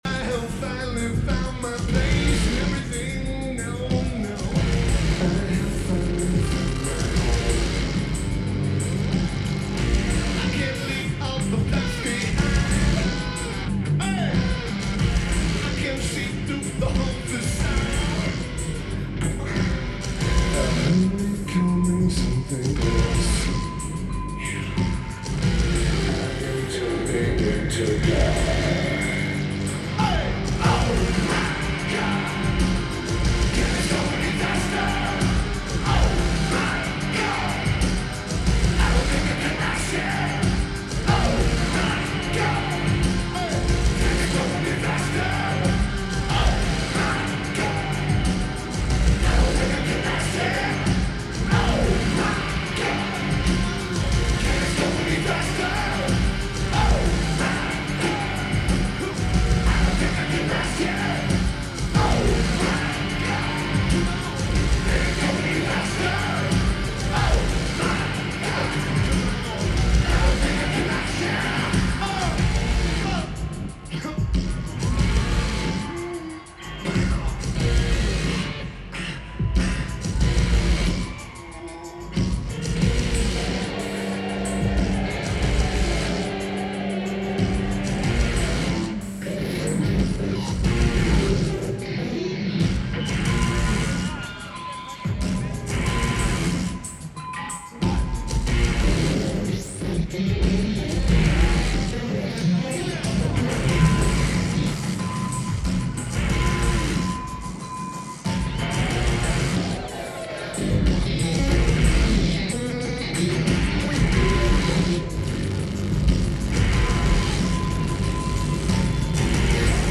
Rose Garden Arena
Lineage: Audio - AUD (SP-CMC4 Hypers + SP-SPSB-6 + JB3)